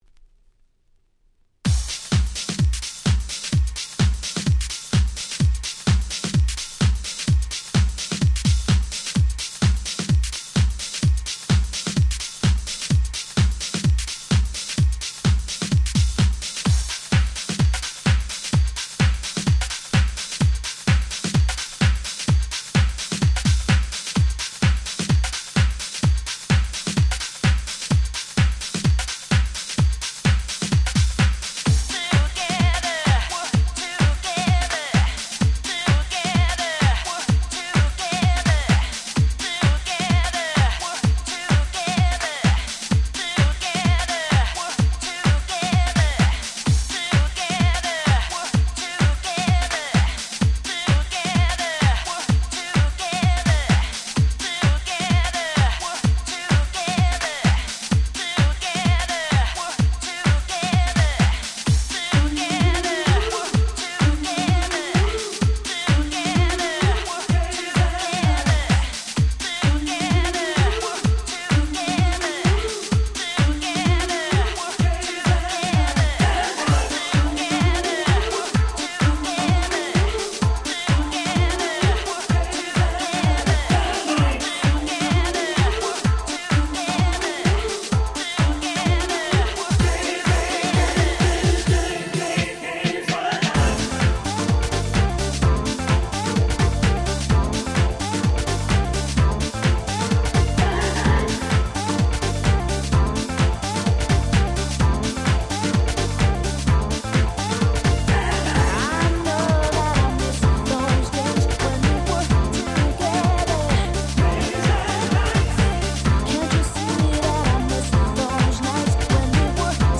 00' Super Nice Vocal House !!